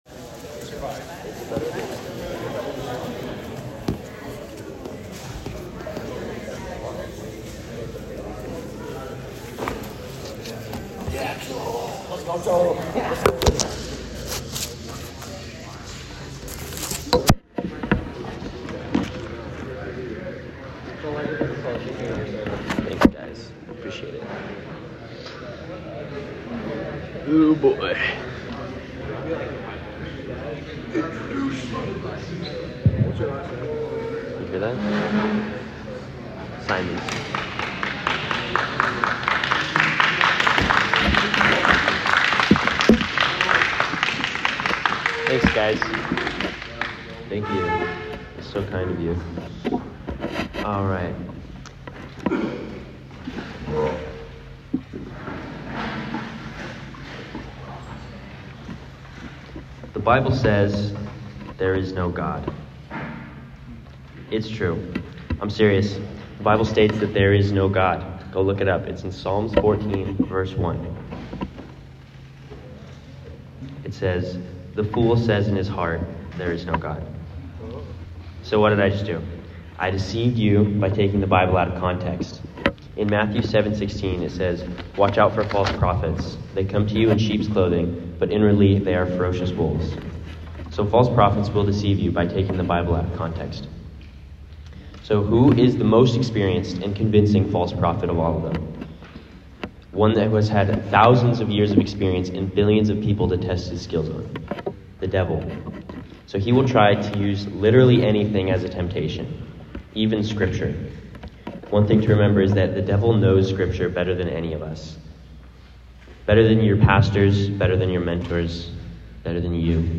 So, on the last day, I got in front of all the gap year guys, and each of the foremen from Samaritan’s Purse and gave a devotional that I spent a few hours writing and coming up with the day before.